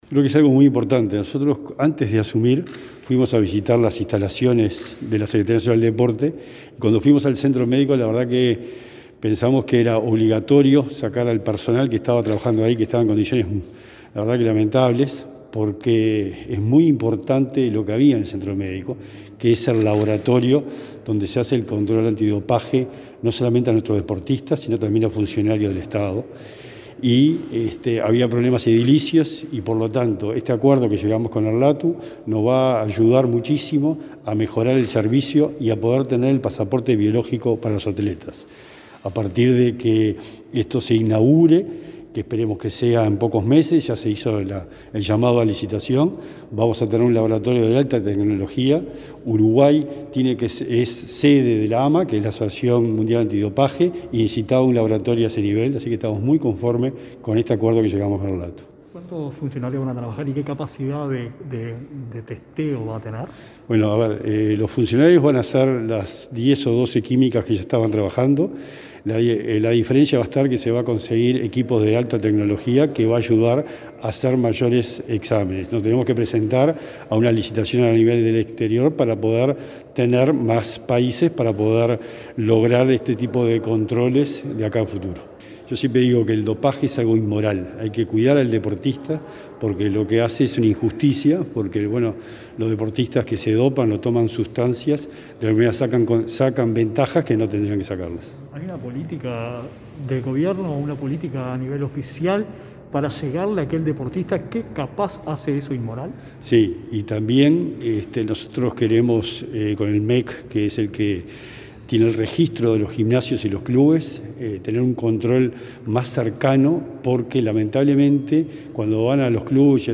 Declaraciones del secretario del Deporte, Sebastián Bauzá, sobre acuerdo con LATU por controles antidopaje